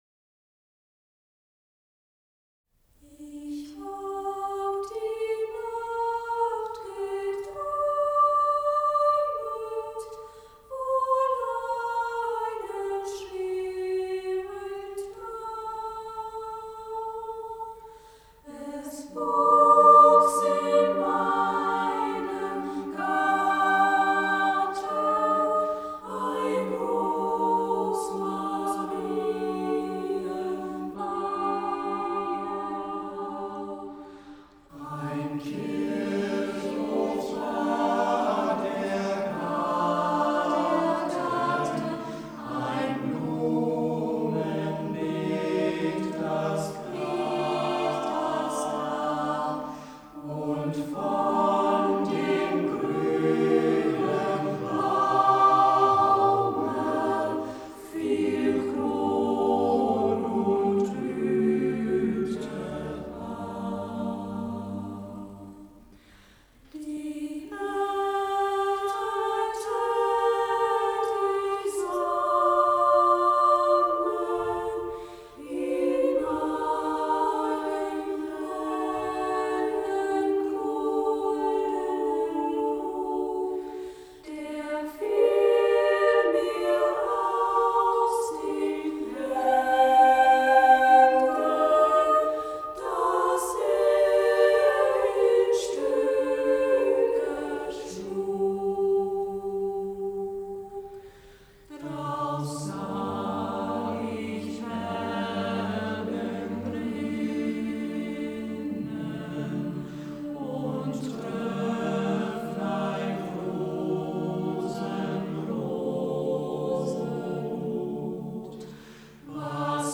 der älteste Jugendchor Berlins. Bei uns singen im Moment 15 Jugendliche und junge Erwachsene im Alter von ca. 16 bis ü30.